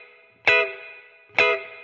DD_TeleChop_130-Emaj.wav